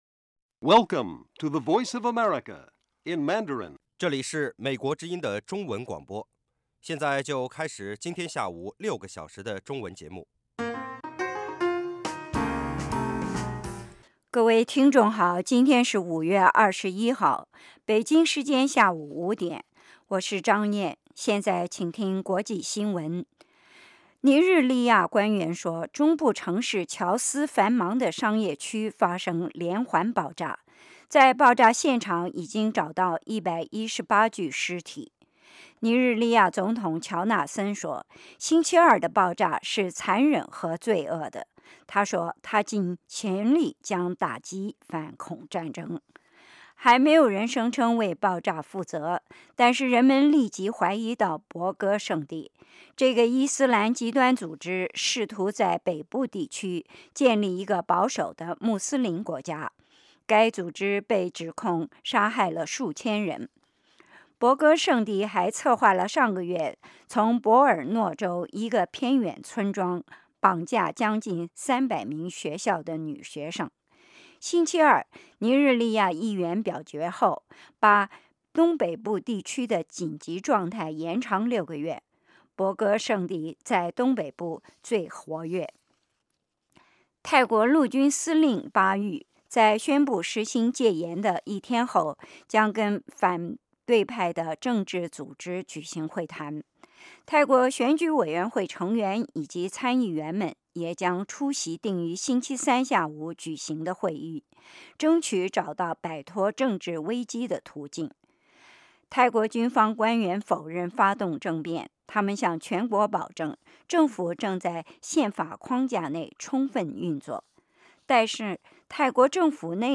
国际新闻 英语教学 社论 北京时间: 下午5点 格林威治标准时间: 0900 节目长度 : 60 收听: mp3